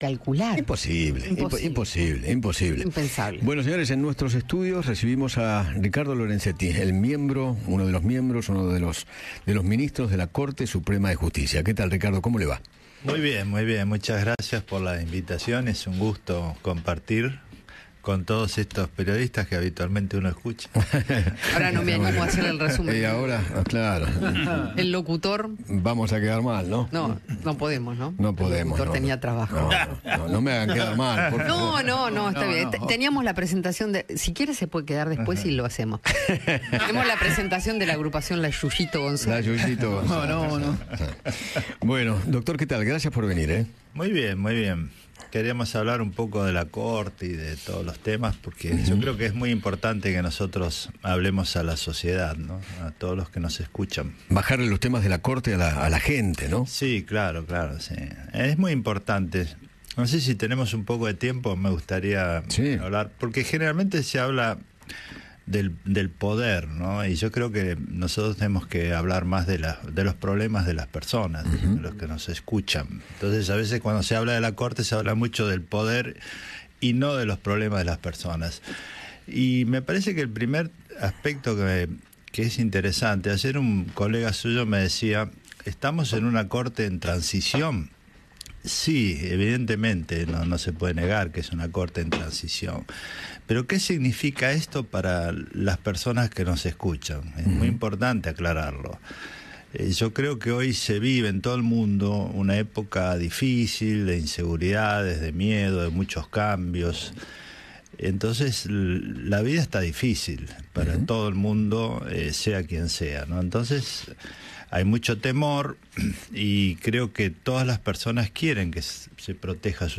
El juez Ricardo Lorenzetti visitó los estudios de Radio Mitre y conversó con Eduardo Feinmann sobre el futuro de la Corte Suprema “de tres” y advirtió que no es lo ideal.